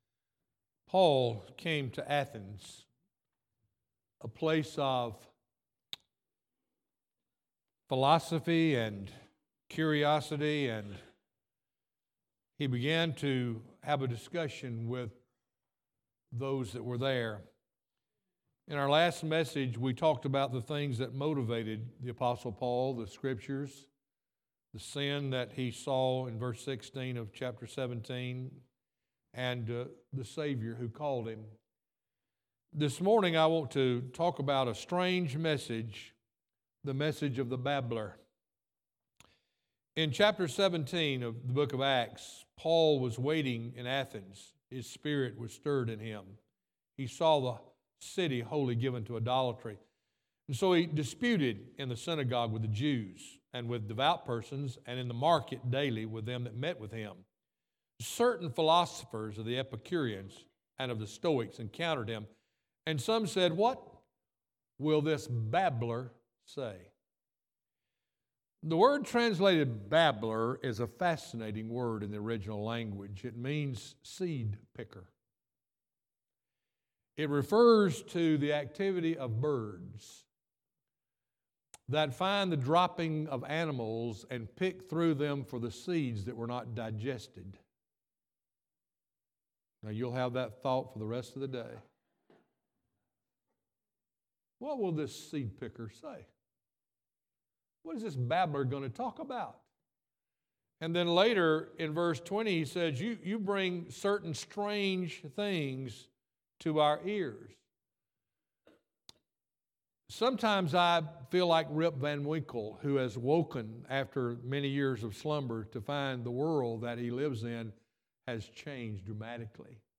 Piney Grove Baptist Church Sermons